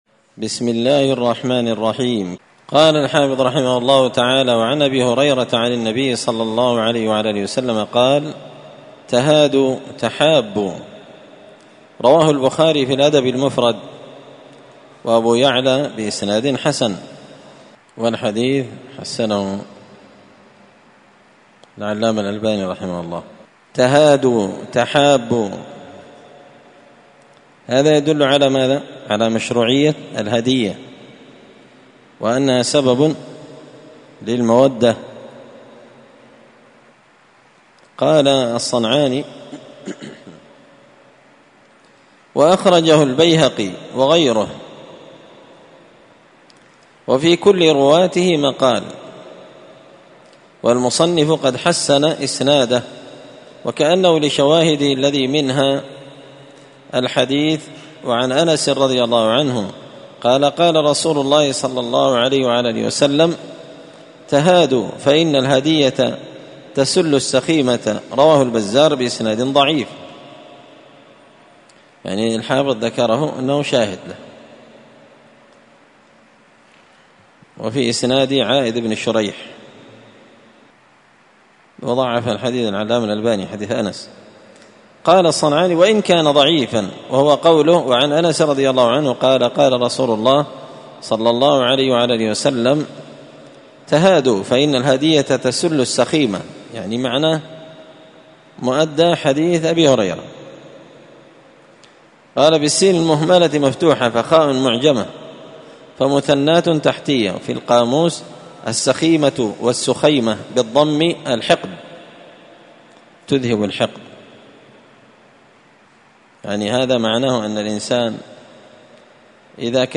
الدرس 132 تابع لباب الهبة والعمرى والرقبى